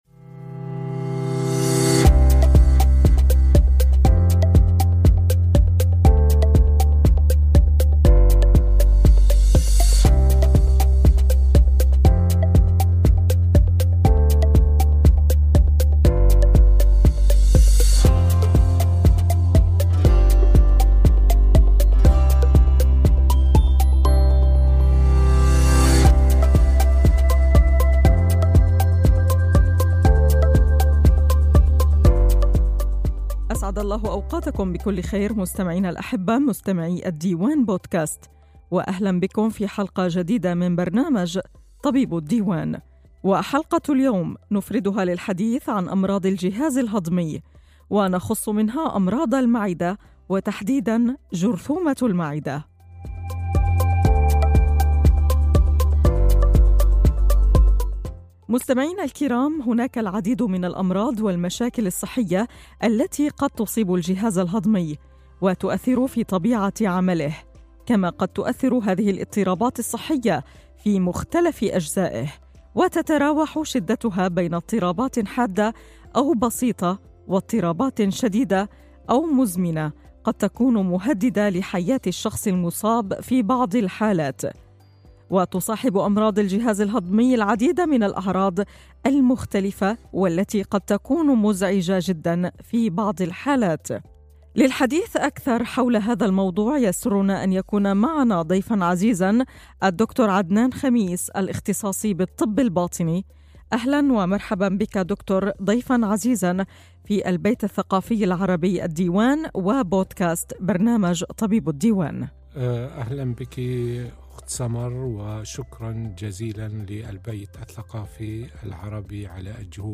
Interessante und nützliche Tipps sollen zu verschiedenen medizinischen Themen besprochen werden. In dieser Podcast-Reihe werden Ärzte aus den unterschiedlichen Fachrichtungen bei wöchentlichen Treffen interviewt.